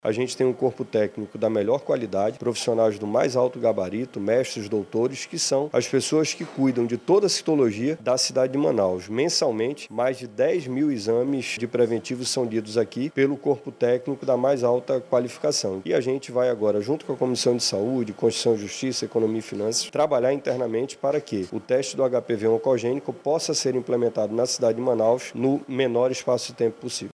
O vereador Marcelo Serafim, integrante da Comissão de Saúde da CMM, ressaltou que os laboratórios contam com boa estrutura.